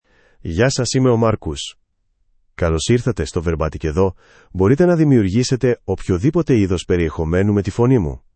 MarcusMale Greek AI voice
Marcus is a male AI voice for Greek (Greece).
Voice sample
Marcus delivers clear pronunciation with authentic Greece Greek intonation, making your content sound professionally produced.